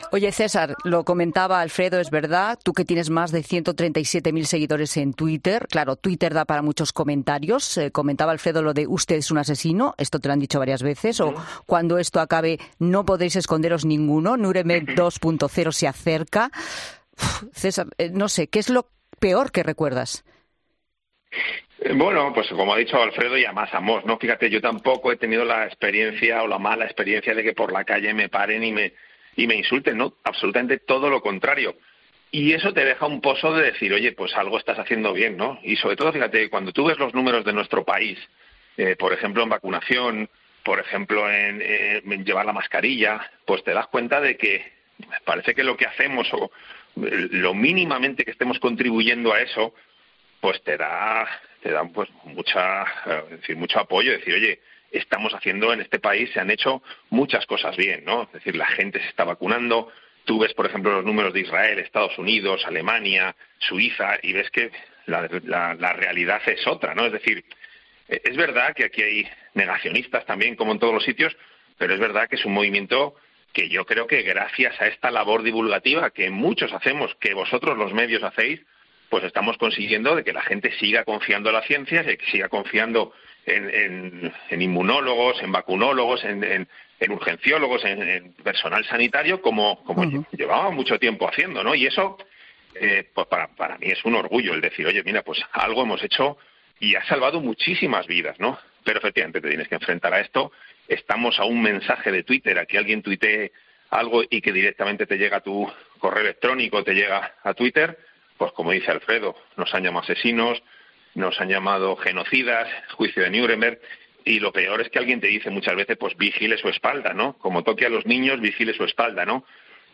En una llamada a 'La Tarde' de COPE